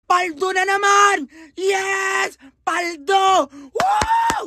paldo nanaman Meme Sound Effect